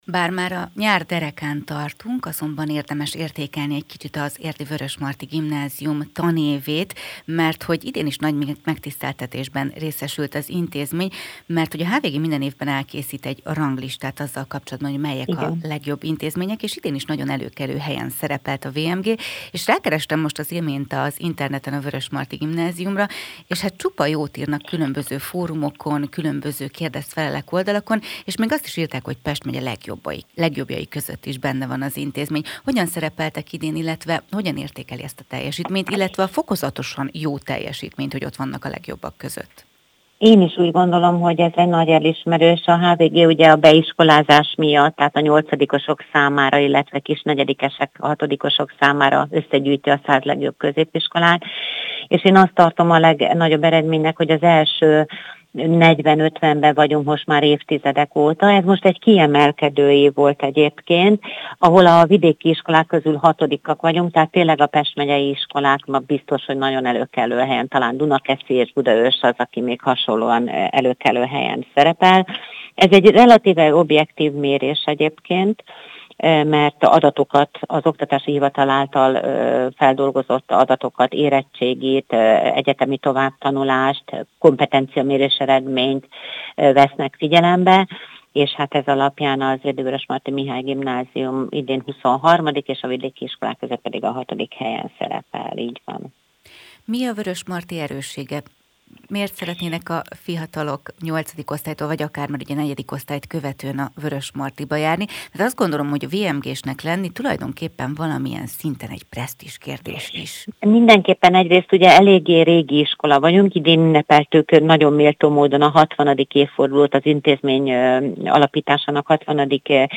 erről is beszélt az elmúlt tanévet értékelő interjújában, amit teljes hosszában itt tud meghallgatni. https